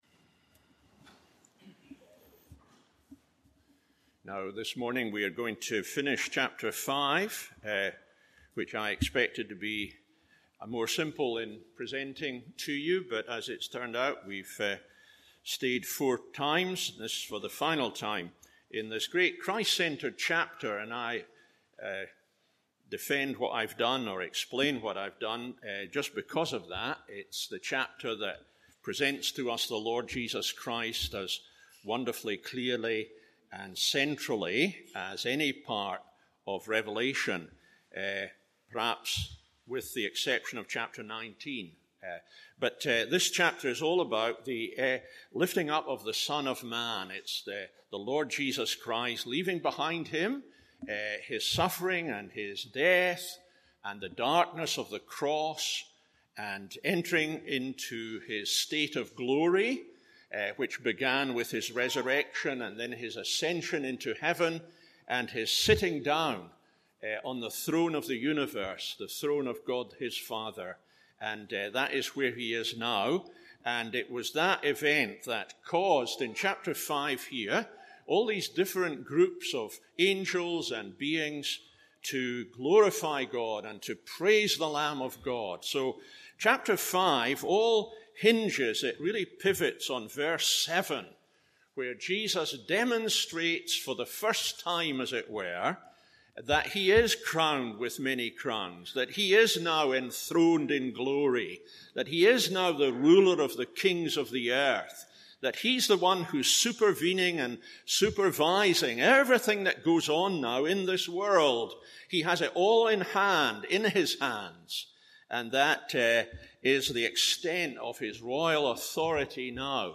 MORNING SERVICE Revelation 5:11-14…